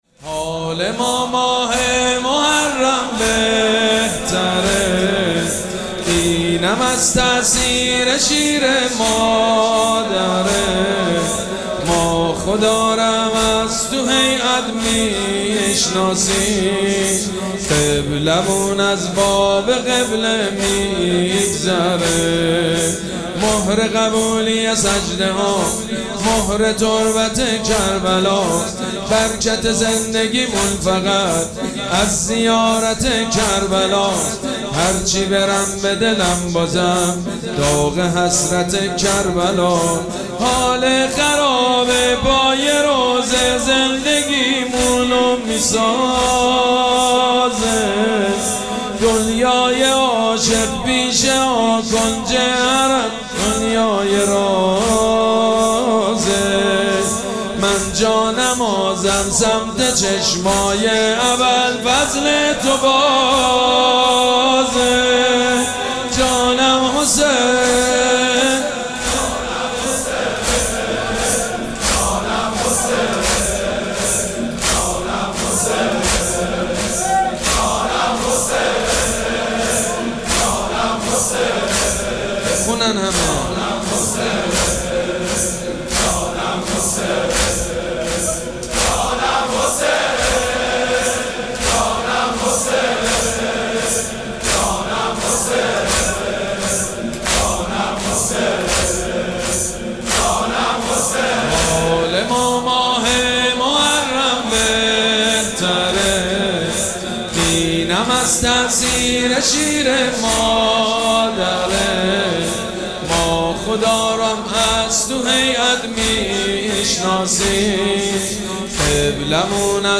مراسم عزاداری شب ششم محرم الحرام ۱۴۴۷
مداح
حاج سید مجید بنی فاطمه